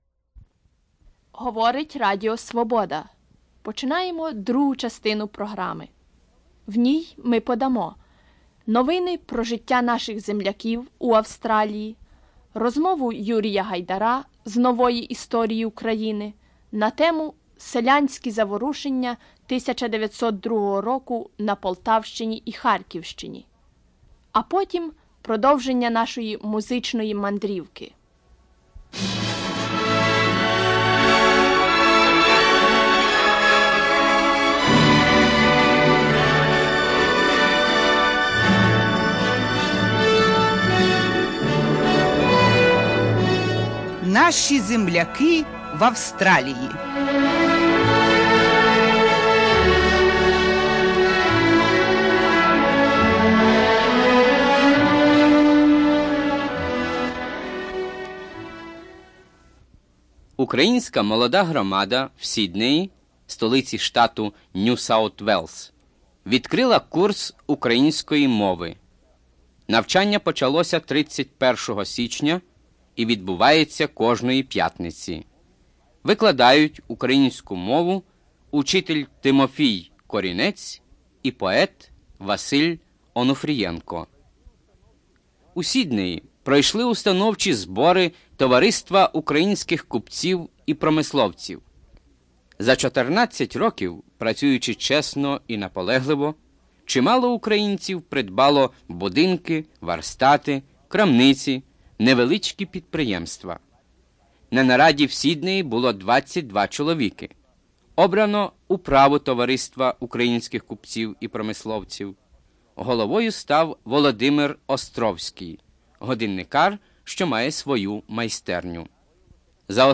Radio Liberty program: Ukrainians in Sydney, Australia; 1902 peasant uprising; the music of Brazil
Musical segment: Music of Brazil